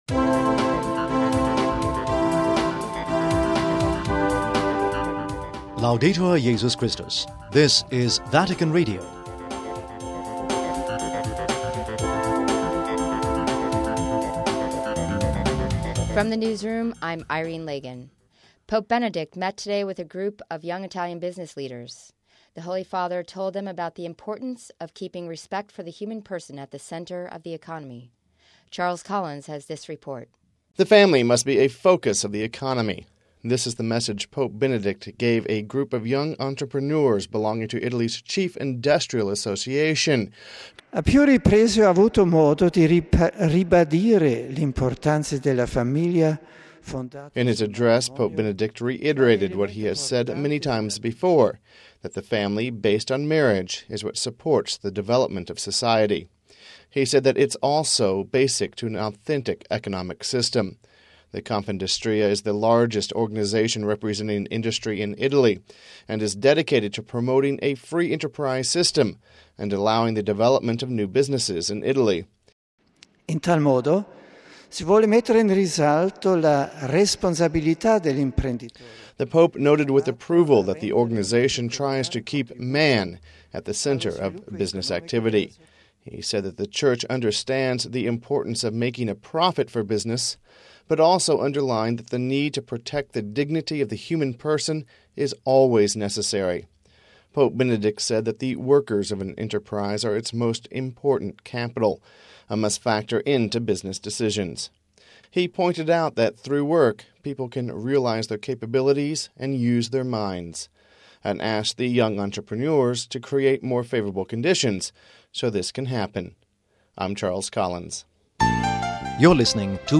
Home Archivio 2007-05-26 15:10:56 Pope Speaks to Business Leaders (26 May 07 - RV) Pope Benedict XVI spoke to a group of young Italian business leaders on Saturday. We have this report....